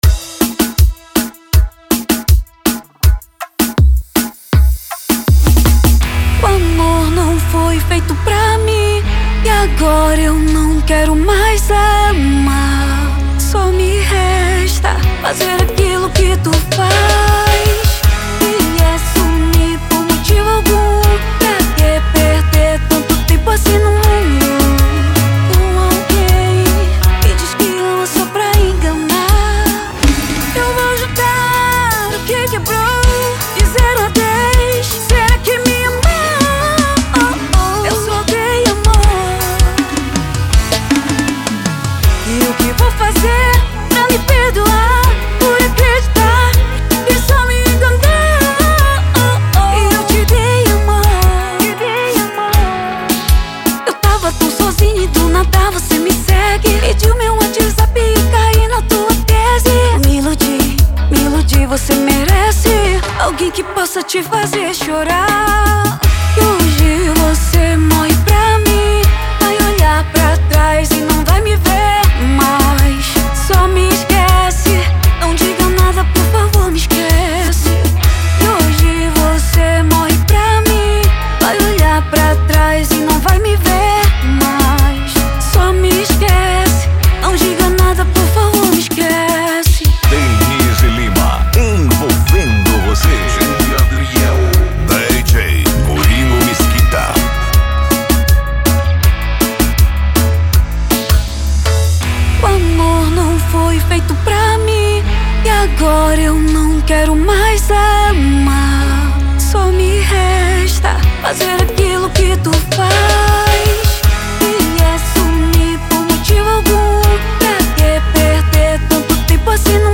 OUÇA NO YOUTUBE Labels: Melody Facebook Twitter